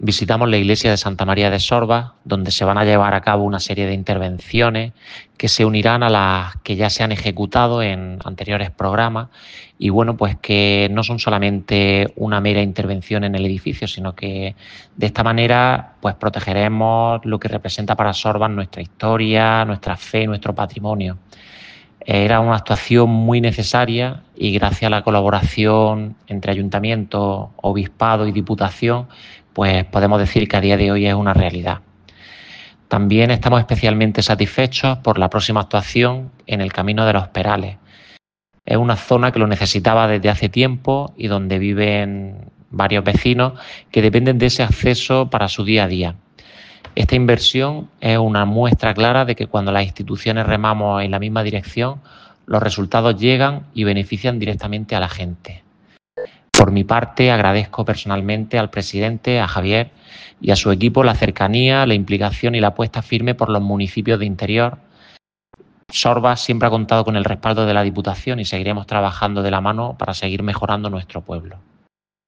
Audio-alcalde-de-Sorbas.mp3